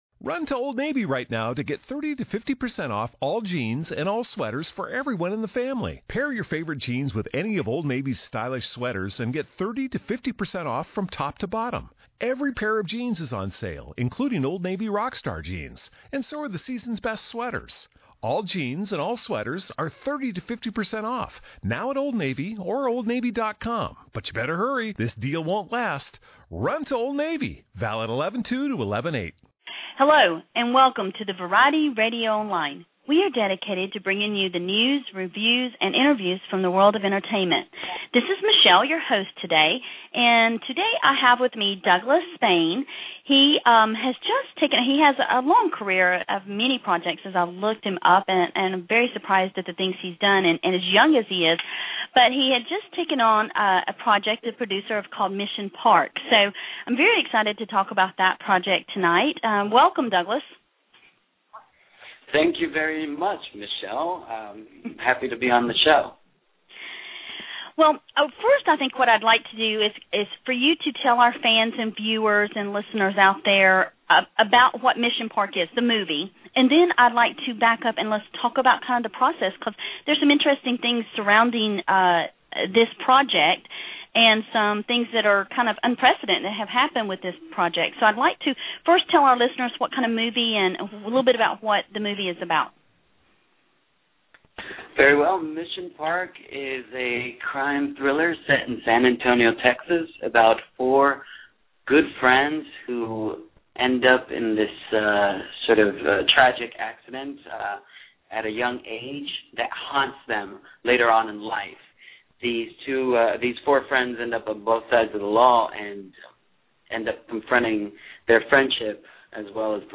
Douglas Spain - Interview